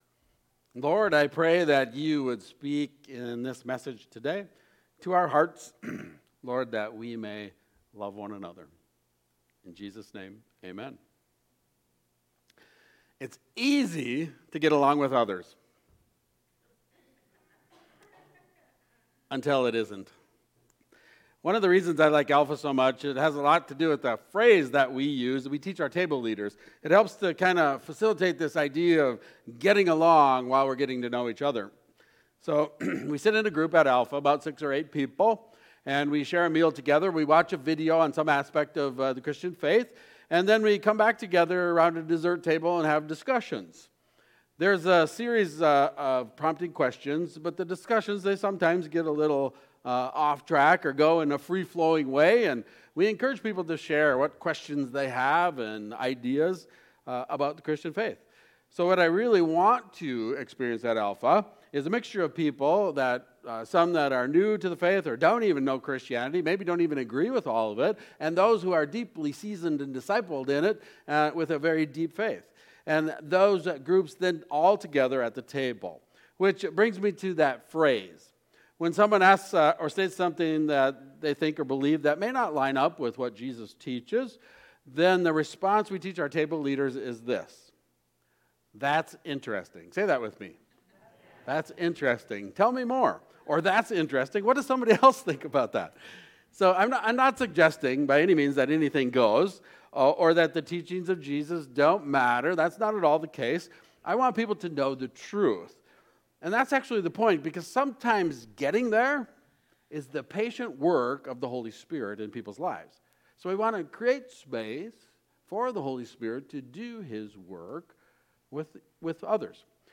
Sermons – Bethlehem Covenant Church podcast